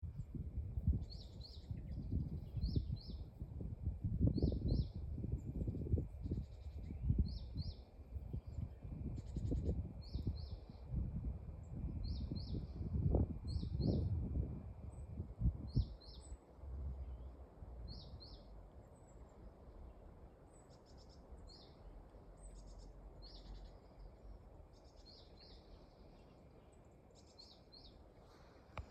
Bird Aves sp., Aves sp.
Administratīvā teritorijaStrenču novads
StatusSinging male in breeding season